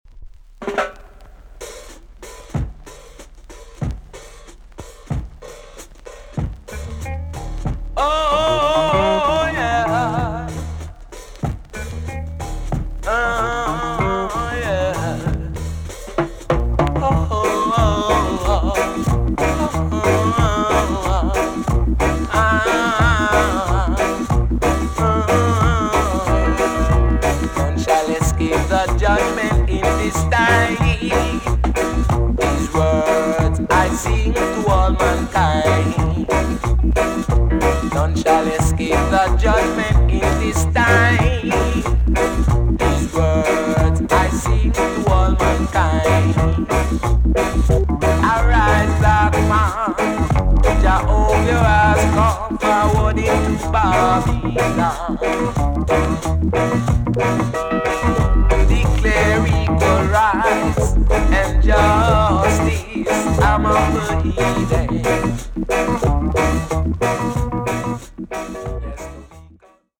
B.SIDE EX- 音はキレイです。